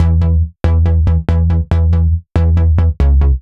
Index of /musicradar/french-house-chillout-samples/140bpm/Instruments
FHC_NippaBass_140-E.wav